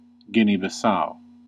1. ^ /ˌɡɪni bɪˈs/
En-us-Guinea-Bissau.ogg.mp3